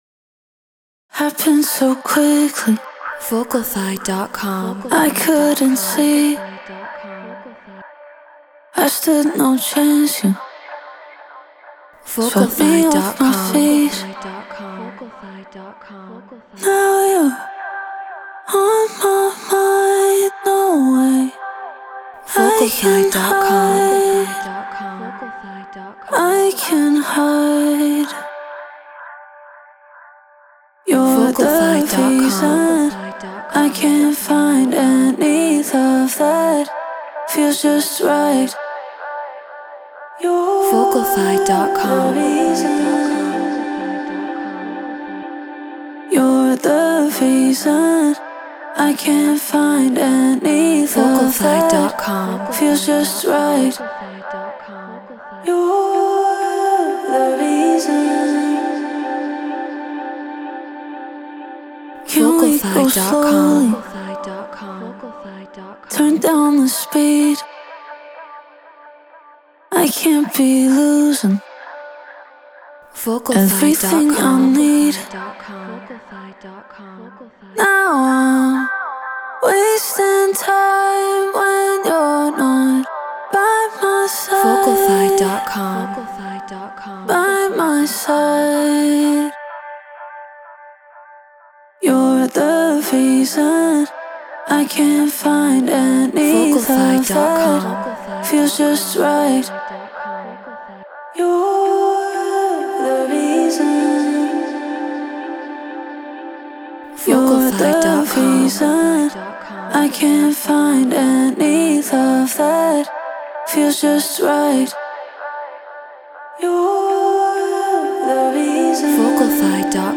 Garage 126 BPM Cmaj
Shure SM7B Apollo Solo Logic Pro Treated Room